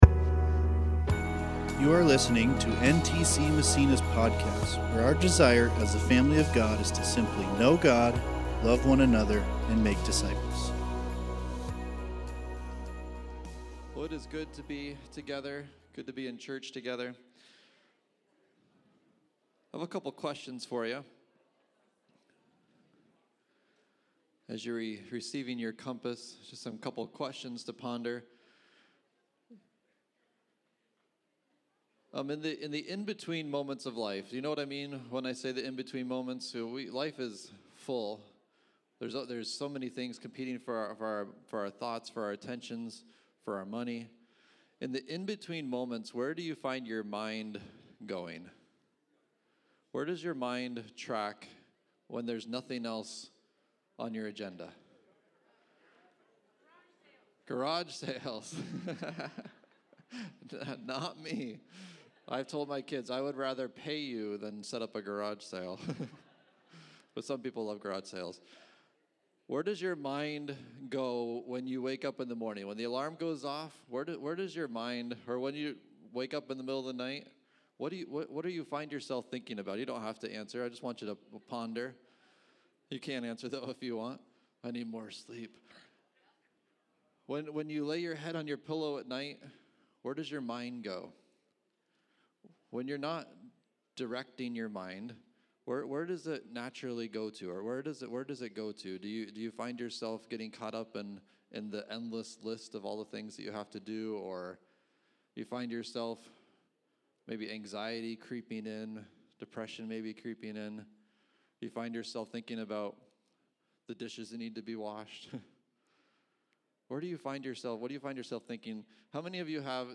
2024 Practicing the Way Preacher